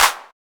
TAB_CLAP_03.wav